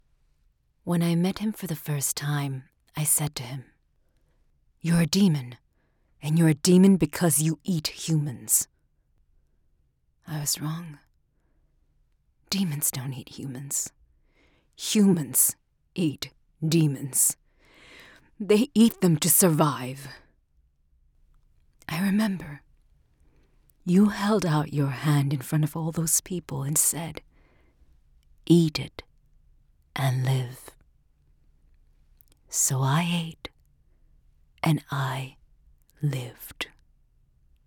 Female
Bilingual Japanese and English speaker.
Video Games
Lower Register, Raspy, Husky